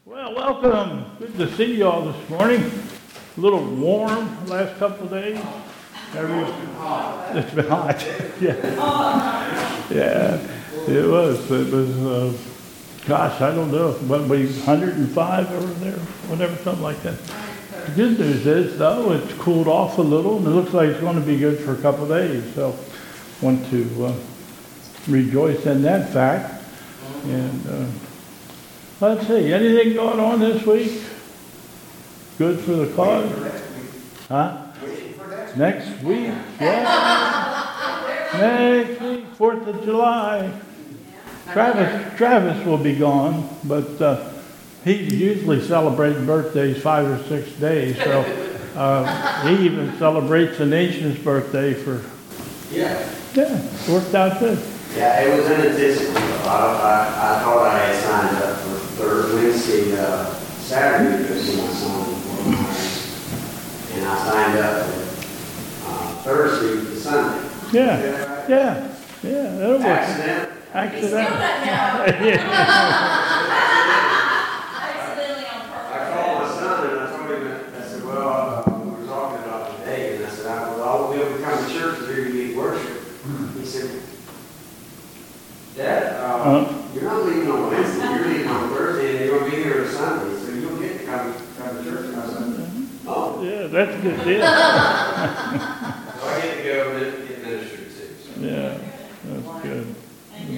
2022 Bethel Covid Time Service
Announcements